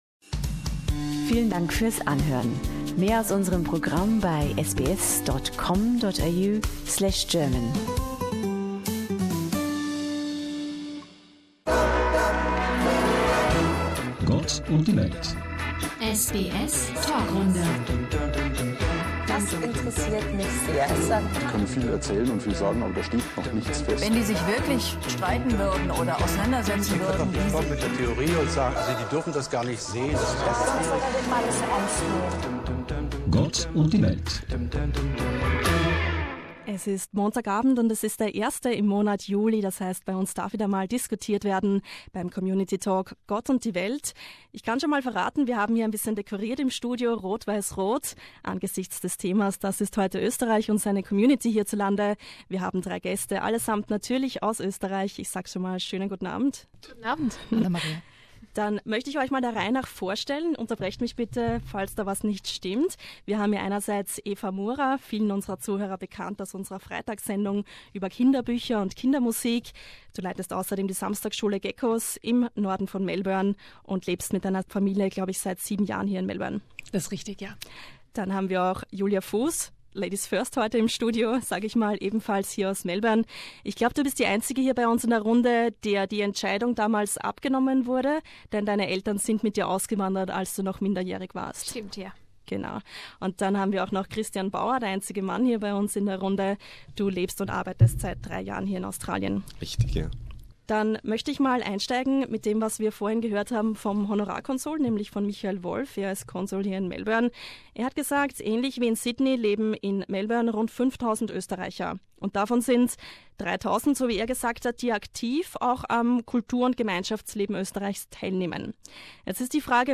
SBS Panel Talk: Being Austrian in Australia